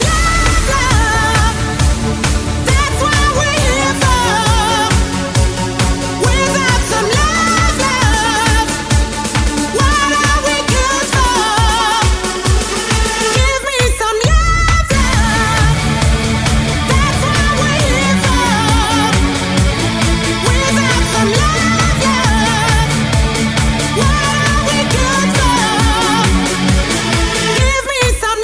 remix & successi internazionali 2008
Genere: dance, house, electro, club, remix, 2008